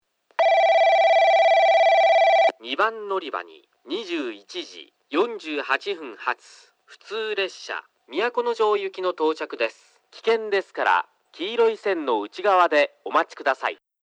放送はJACROS簡易詳細型で、接近ベルが鳴ります。
スピーカーはFPS平面波です。なお放送の音割れが激しいですがこれは元からで、夜間音量の方が綺麗に聞こえます。
2番のりば接近放送（都城行き）